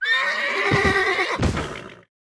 pony_dead.wav